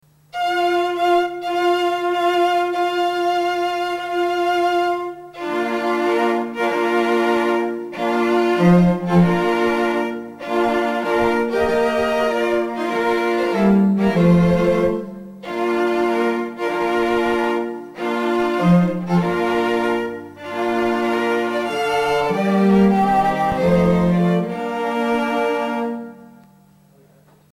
Comprised of four professional musicians, this highly-respected string quartet have been performing together for over 20 years, and the sophisticated sounds of their beautifully-harmonised strings create a magical, romantic atmosphere for wedding ceremonies or receptions.
• Professional string quartet